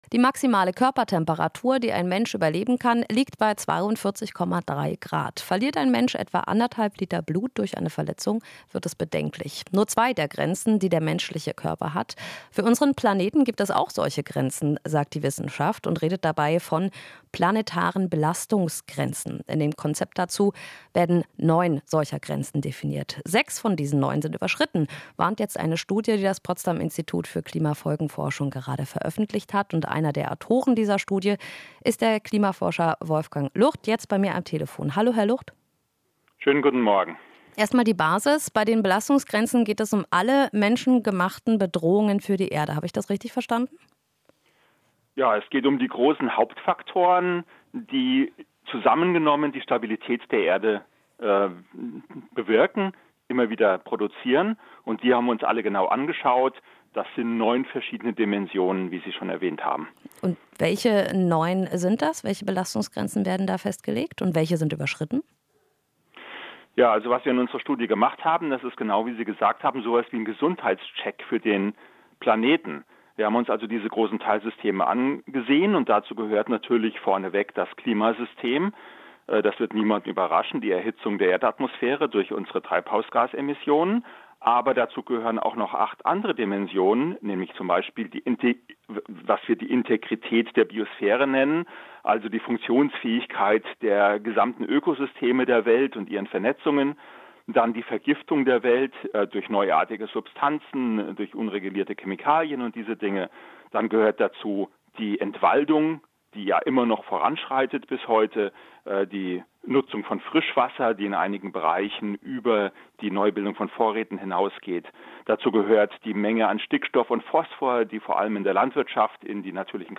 Interview - PIK warnt vor Umweltbelastungen: "Die Erde ist ins Rutschen gekommen"